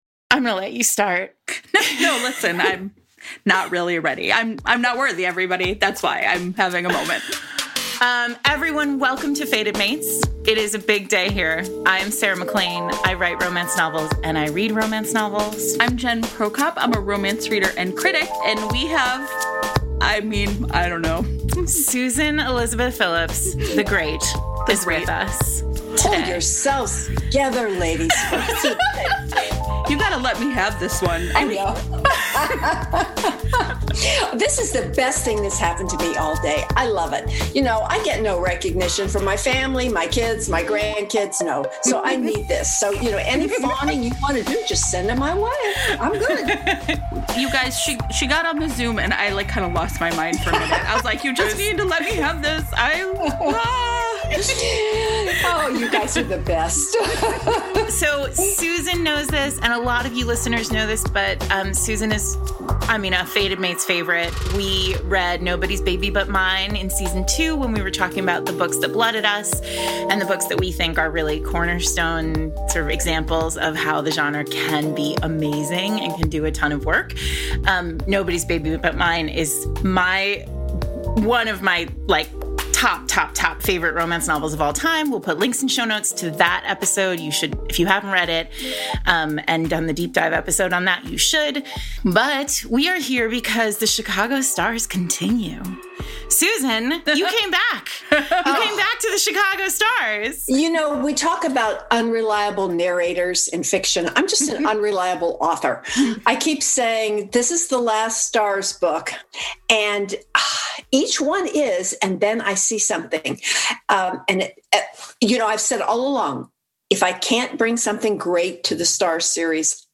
S03.46: Susan Elizabeth Phillips Interview